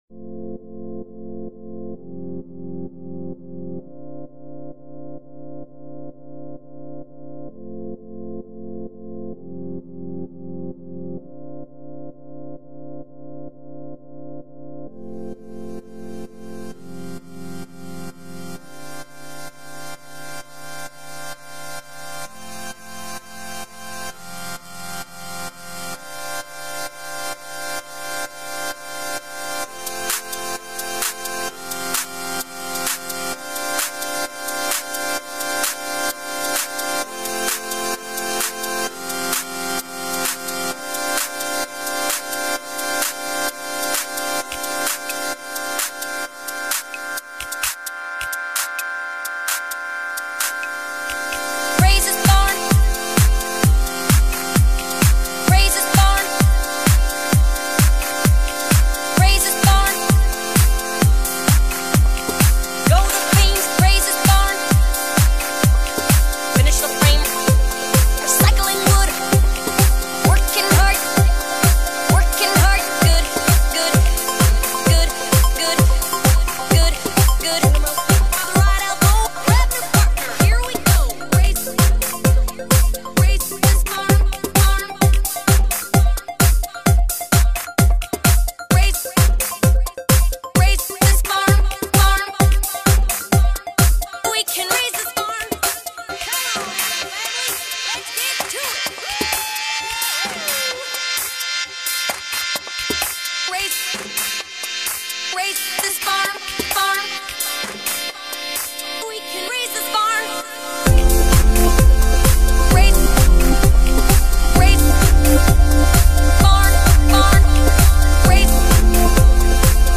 genre:house